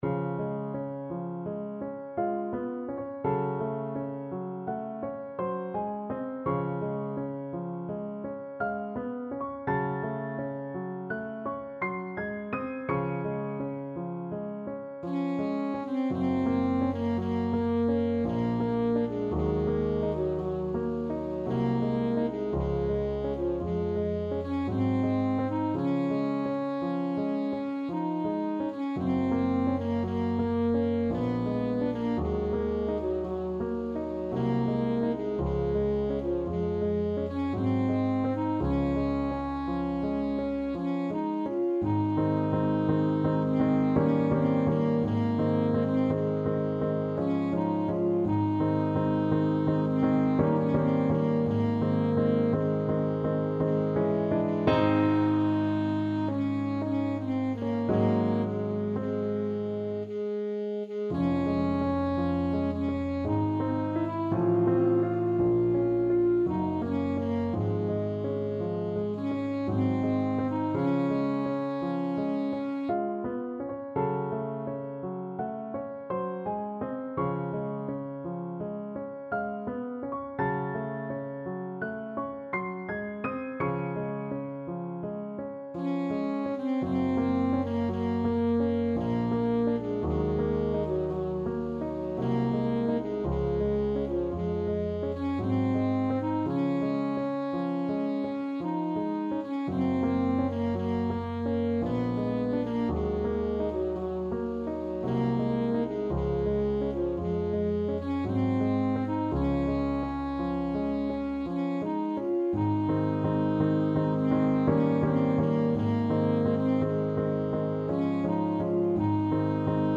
Classical Schubert, Franz Lob der Tranen, D.711 Alto Saxophone version
Alto Saxophone
Db major (Sounding Pitch) Bb major (Alto Saxophone in Eb) (View more Db major Music for Saxophone )
3/4 (View more 3/4 Music)
~ = 56 Ziemlich langsam
Classical (View more Classical Saxophone Music)